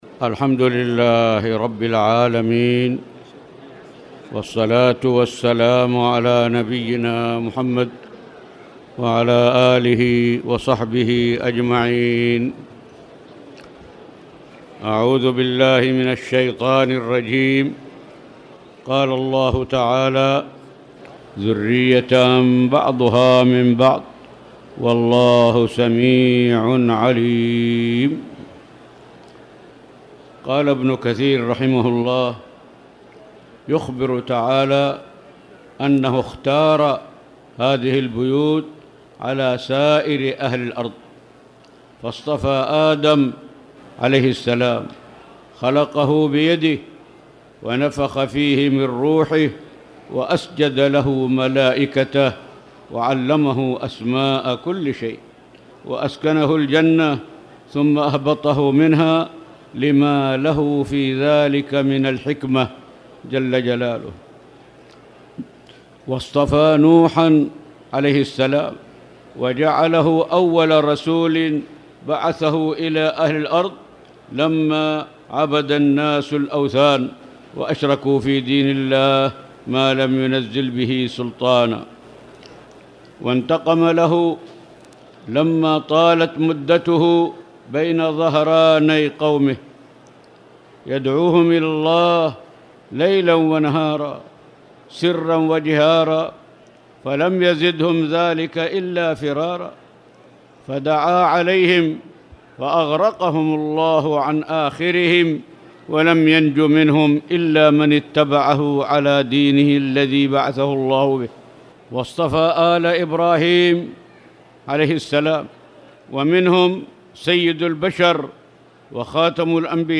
تاريخ النشر ٢٥ جمادى الآخرة ١٤٣٨ هـ المكان: المسجد الحرام الشيخ